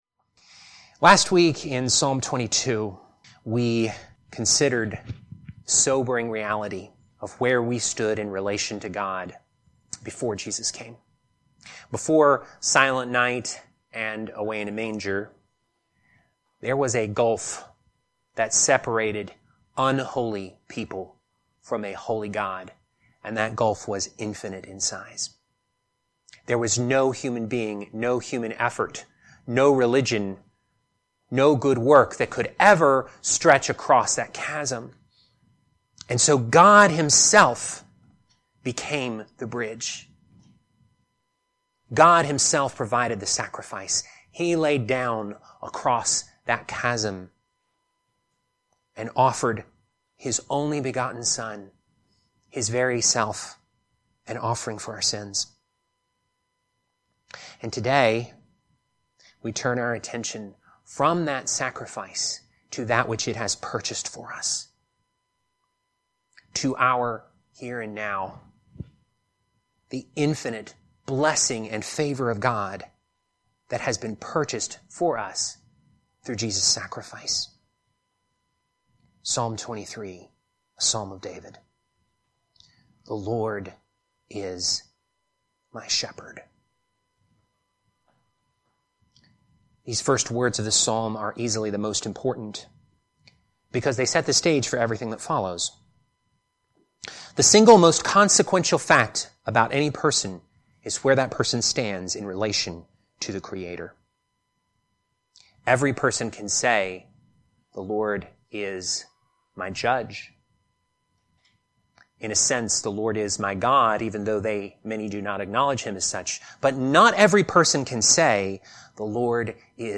Teaching For December 21, 2025